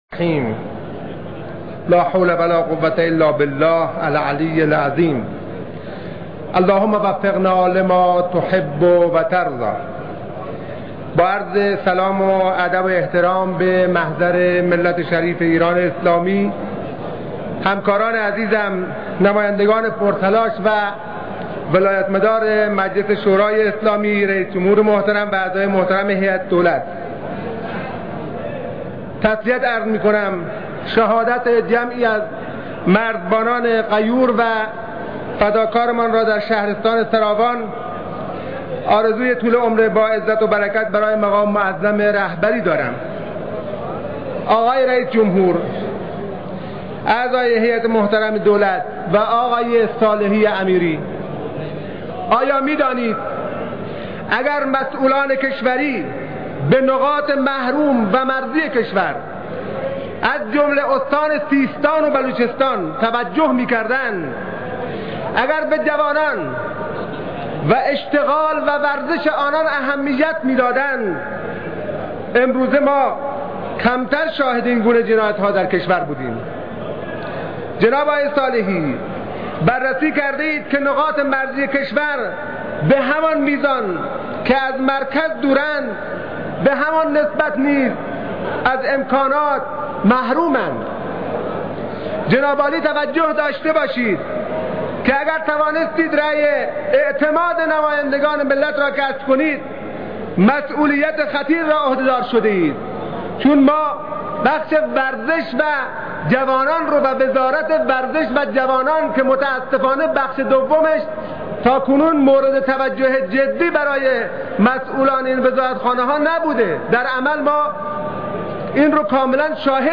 به گزارش «نمایندگان» به نقل از فارس، باقر حسینی نماینده مردم زابل و زهک در مجلس شورای اسلامی در جلسه علنی (یکشنبه) پارلمان در سخنانی به عنوان اولین مخالف برنامه‌های سیدرضا صالحی‌امیری وزیر پیشنهادی آموزش و پرورش اظهار داشت: آیا می‌دانید اگر مسئولان کشوری به نقاط محروم و مرزی کشور از جمله سیستان و بلوچستان توجه می‌کردند، اگر به جوانان و اشتغال و ورزش آنان اهمیت می‌دادند امروز کمتر شاهد این حوادث در کشور بودیم.